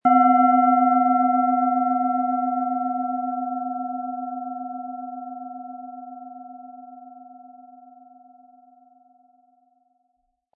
Handgearbeitete tibetische Schale mit dem Planetenton Wasser.
Der gratis Klöppel lässt die Schale wohltuend erklingen.
SchalenformBihar
MaterialBronze